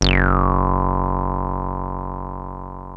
303 F#1 9.wav